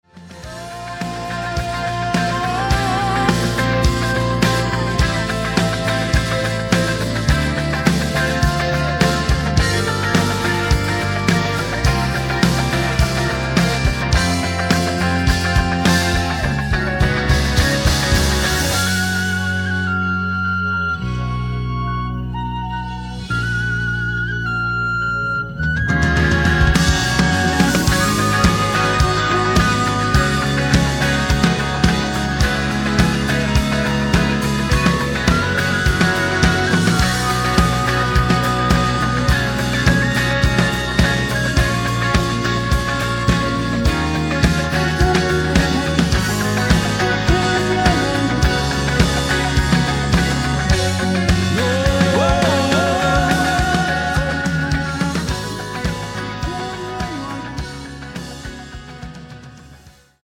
음정 원키 3:25
장르 가요 구분 Voice MR